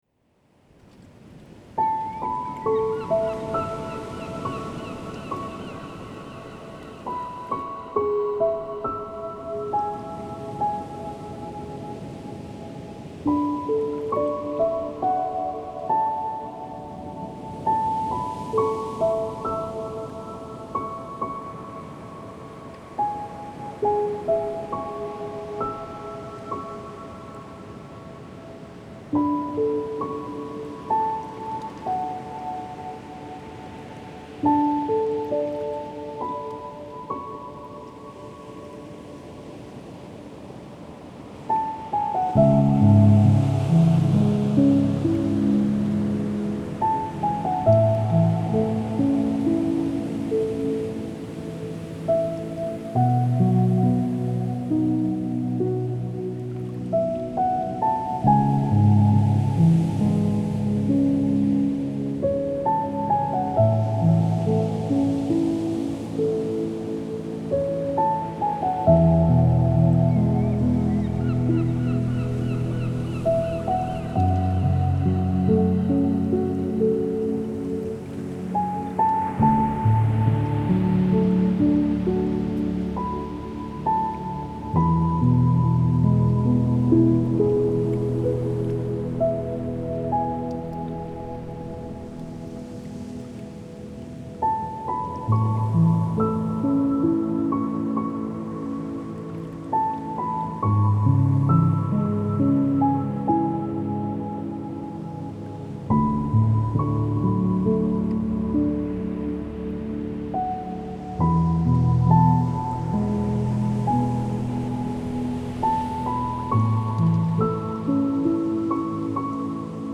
это нежная инструментальная композиция в жанре неоклассики